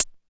28 HAT 4.wav